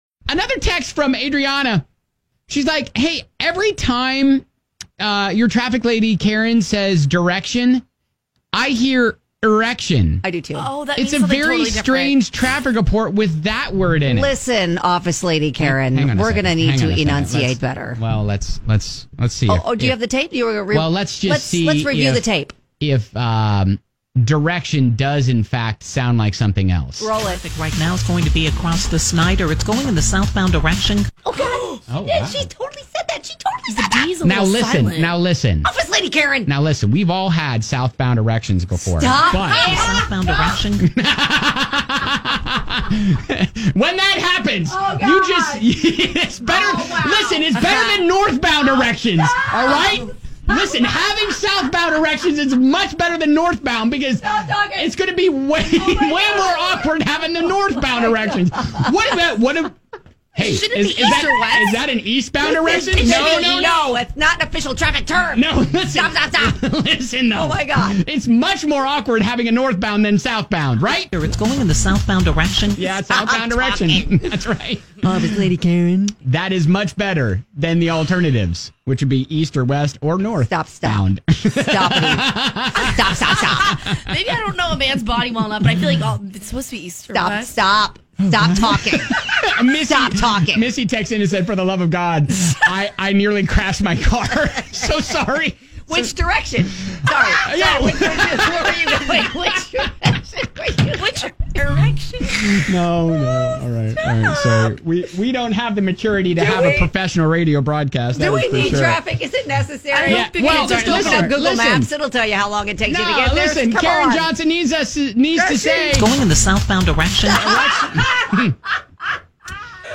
A listener pointed out that whenever our traffic reporter says "direction" it sounds like something else.